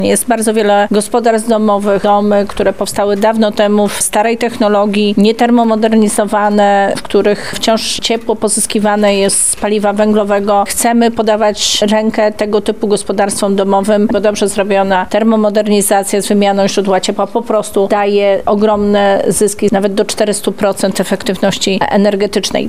Dla województwa lubelskiego program „Czyste powietrze” ma szczególne znaczenie – mówiła podczas briefingu prasowego Minister Klimatu i Środowiska, Paulina Henning-Kloska.